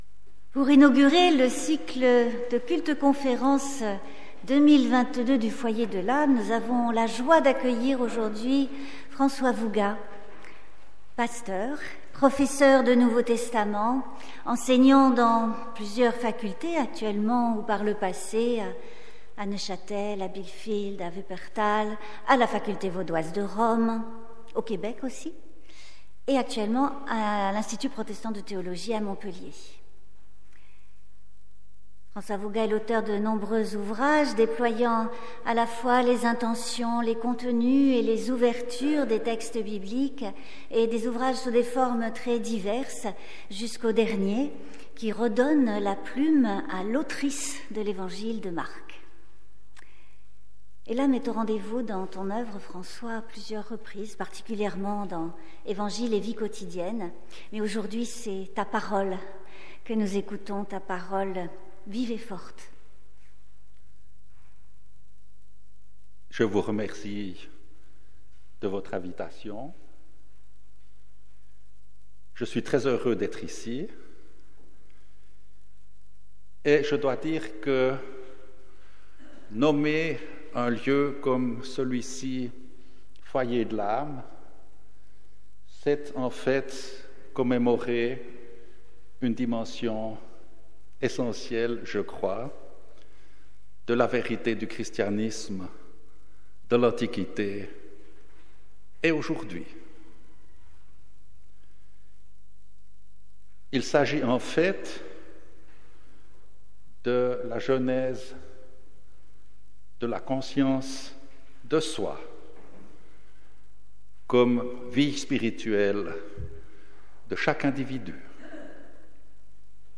Culte-conférence du 23 janvier 2022